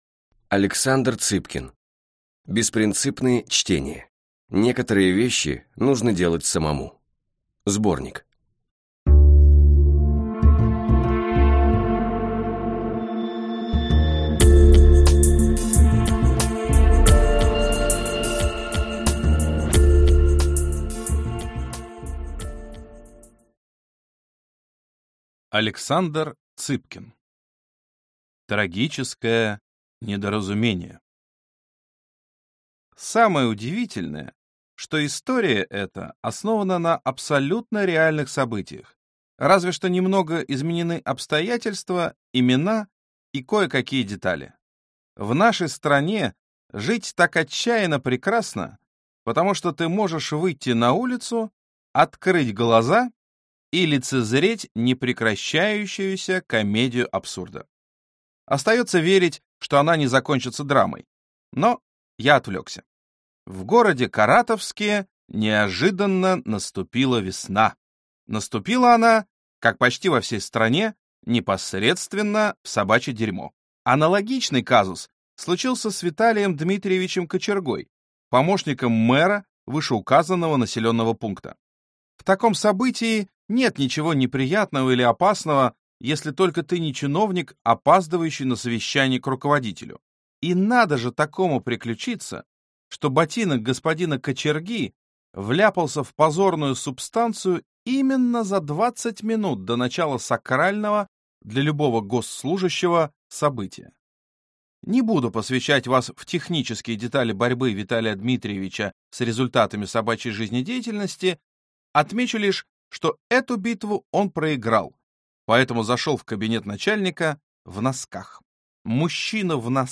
Аудиокнига БеспринцЫпные чтения. Некоторые вещи нужно делать самому | Библиотека аудиокниг